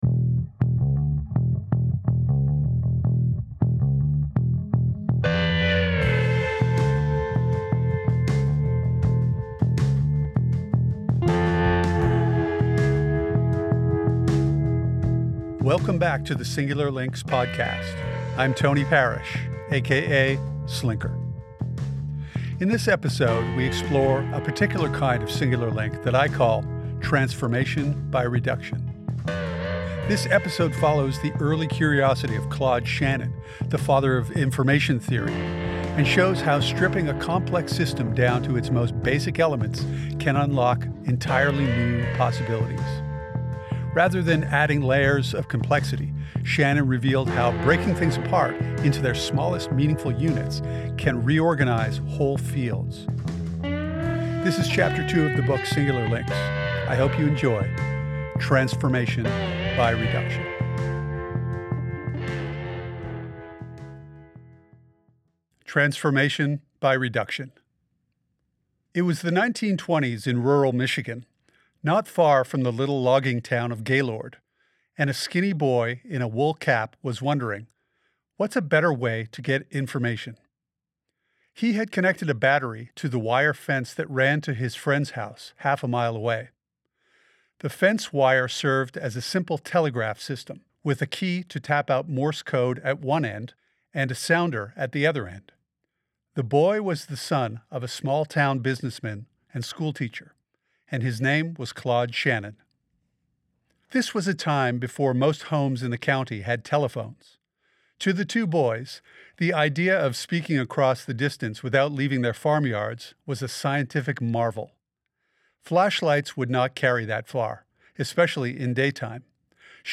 This episode features Chapter Two of the audio book Singular Links.